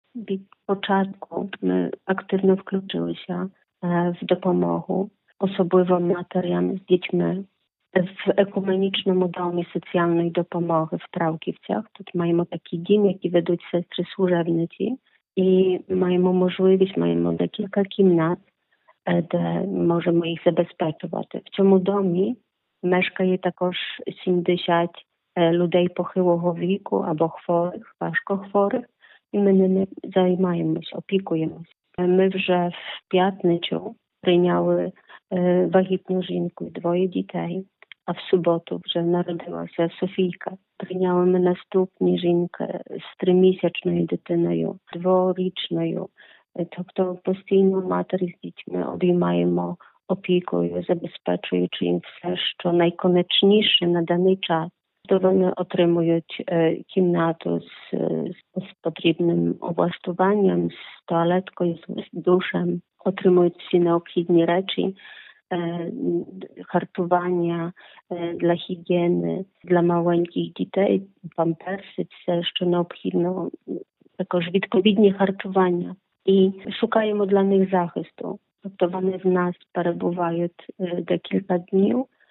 У телефонному інтерв’ю